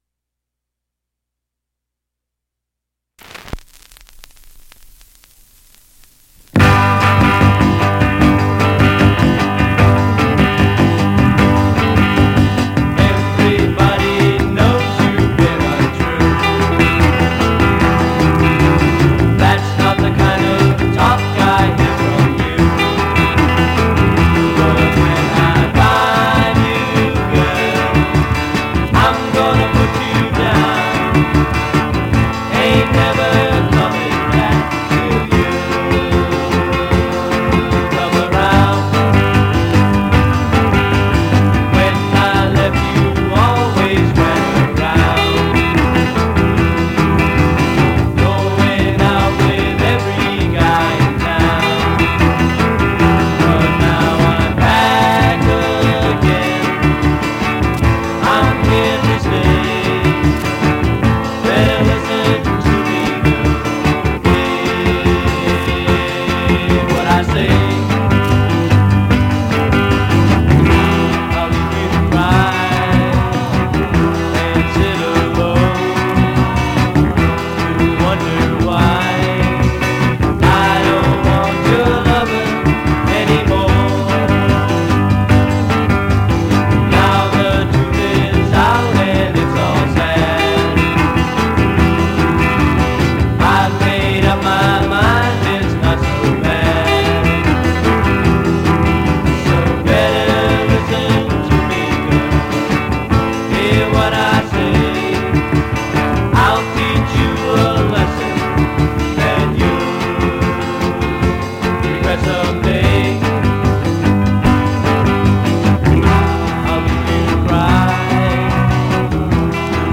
his old band: